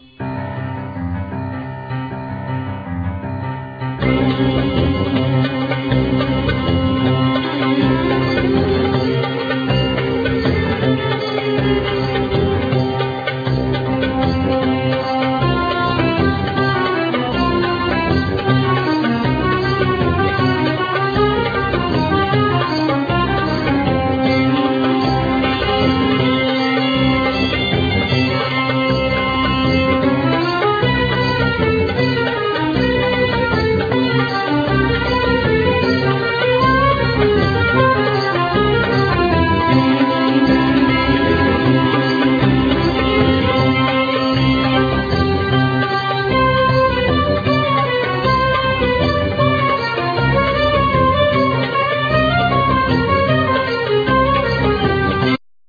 Piano
Accordion
Clarinet
Tuba